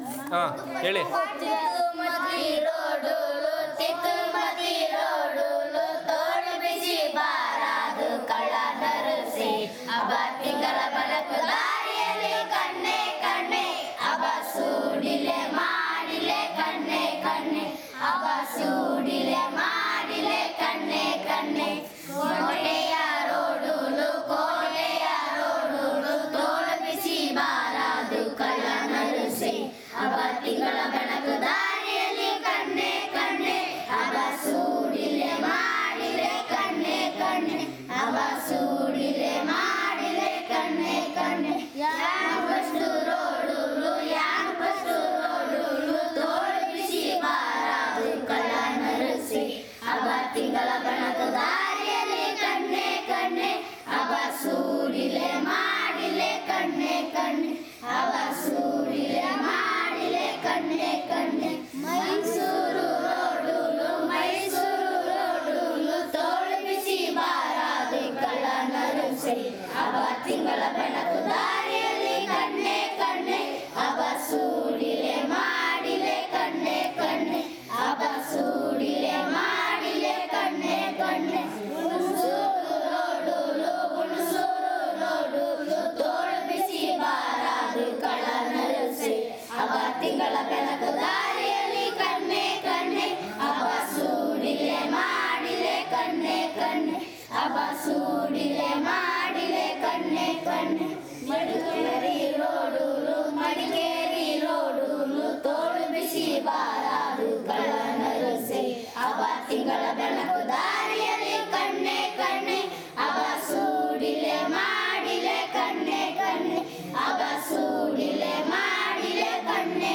Performance of folksong by school children